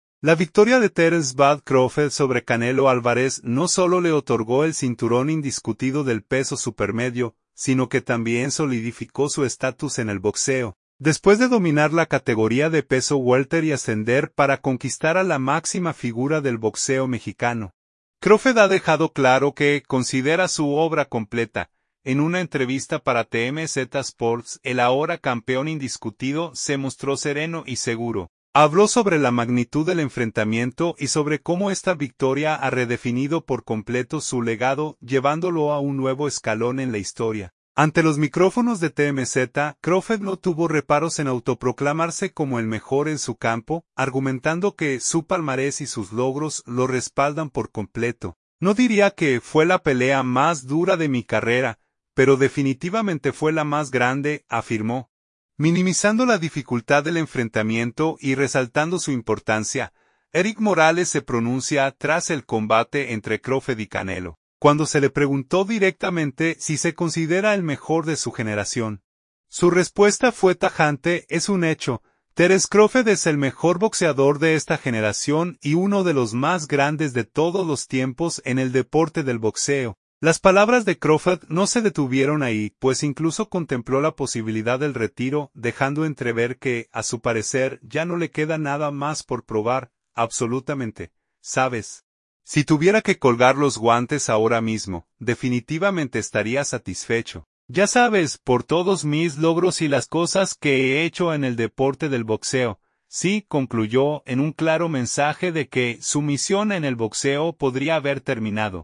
En una entrevista para TMZ Sports el ahora campeón indiscutido se mostró sereno y seguro.